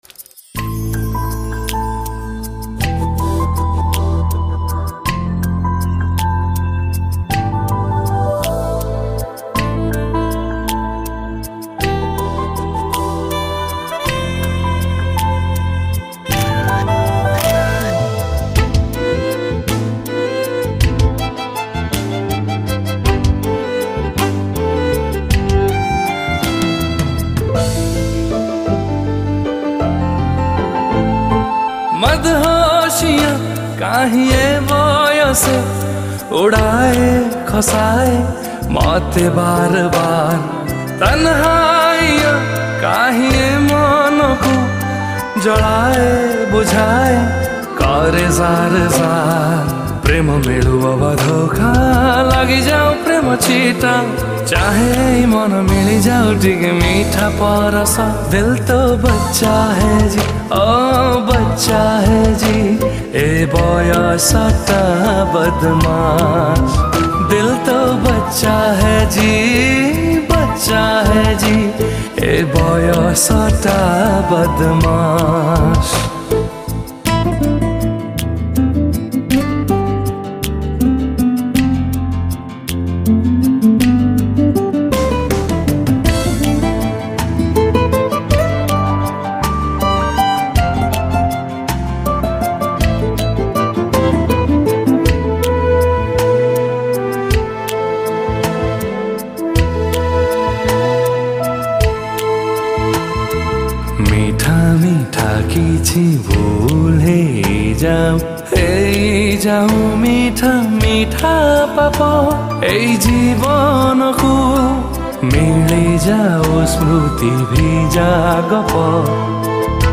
Odia Soft Romantic Song